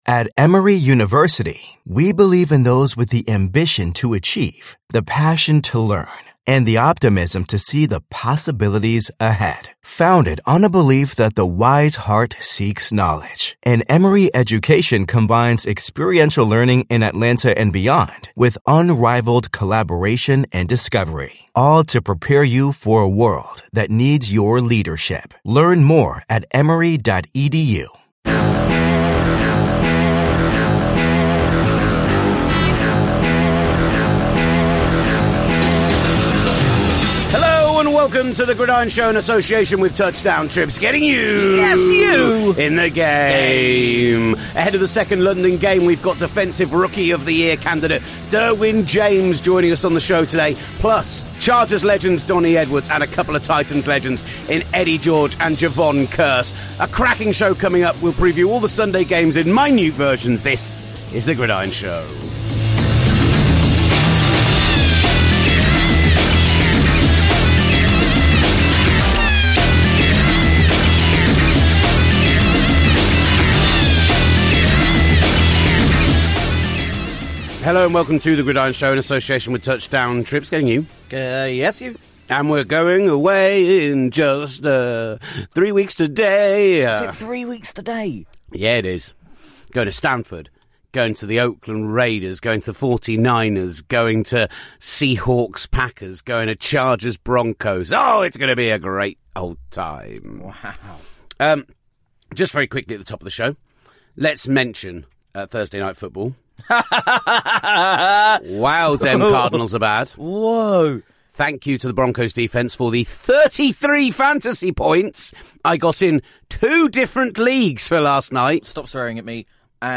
Defensive Rookie of the Year favourite Derwin James speaks exclusively with Gridiron from the team's pre-London training camp in Cleveland, plus we speak with Titans legends Eddie George & Jevon Kearse and former Chargers & Chiefs linebacker Donnie Edwards.